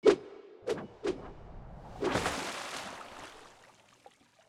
sword 4.wav